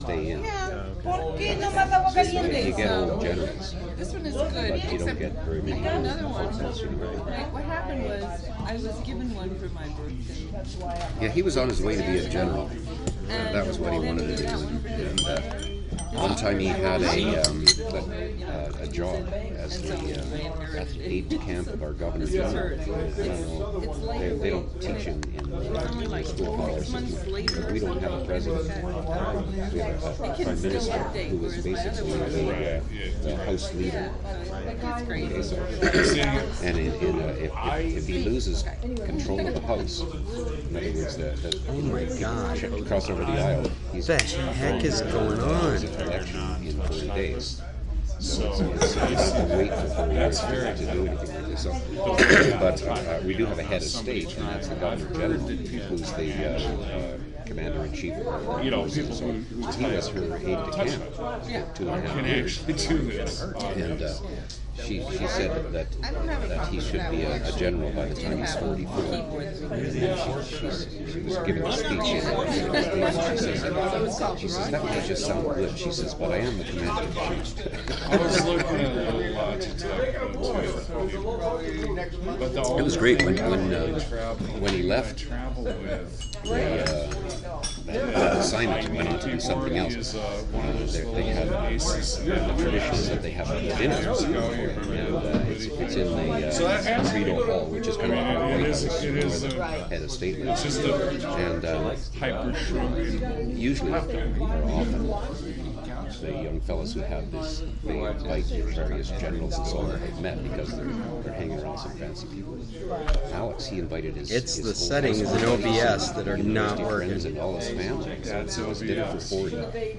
First, this audio recorded had to be lifted from the video archive recording I do locally on my laptop. Miraculously, the audio from the video was recorded without problems.